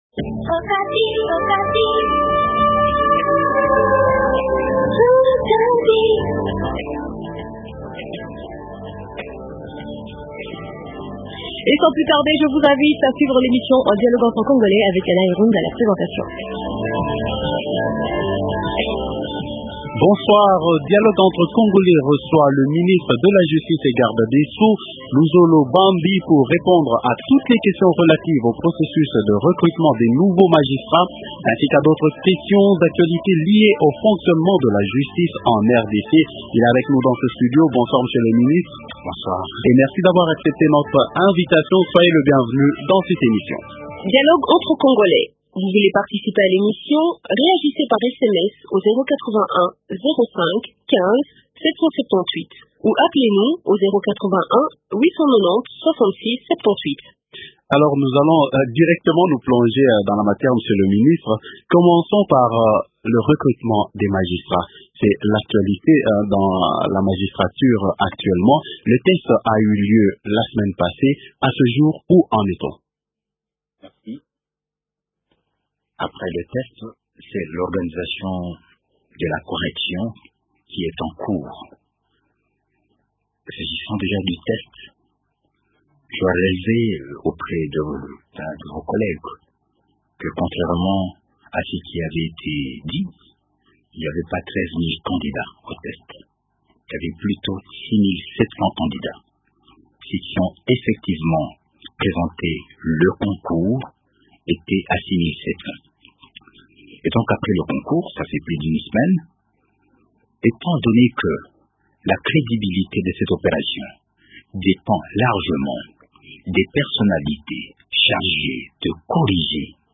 Dialogue entre congolais reçoit ce soir le ministre de la justice Luzolo Bambi.
Invité : -Luzolo Bambi, Ministre de la justice du gouvernement central.